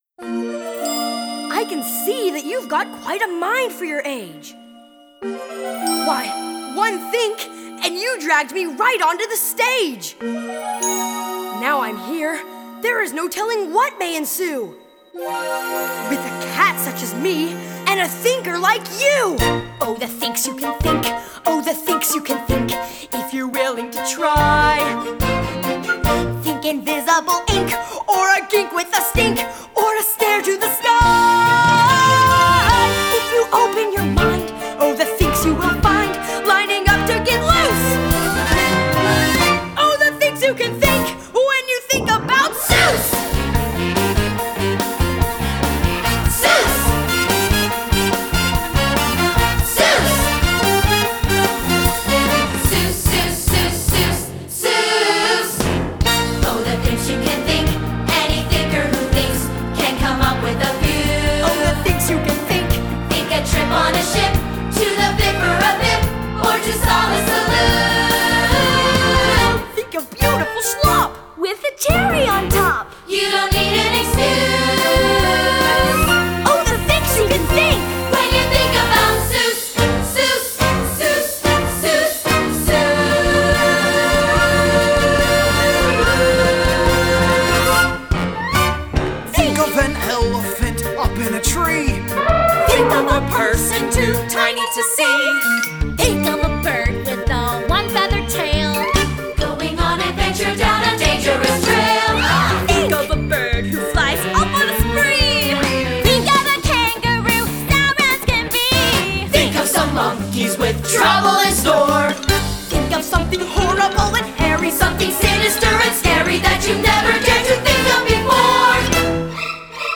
Guide Vocals